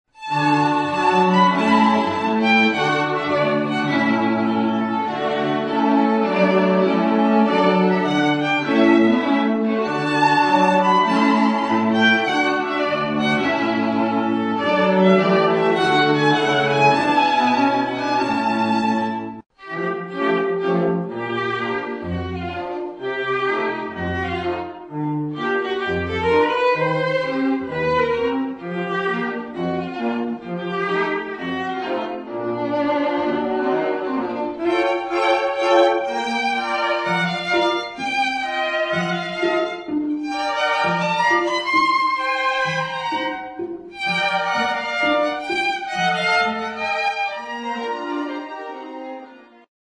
Репертуар дворца | Струнный квартет Нона